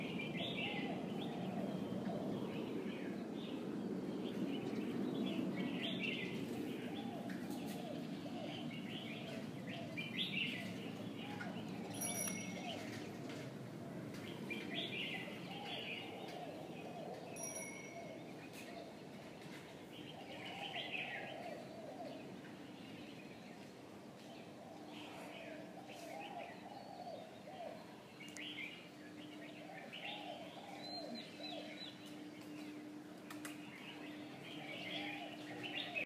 어느 순간 줄리엣의 테라스에서 정말이지 동화 같은 새소리가 들렸다.
매일 아침 새소리와 함께 의식이 돌아오다 보니 곧 익숙해졌지만, 아무튼 초반에는 그랬다.
새소리가 다했어.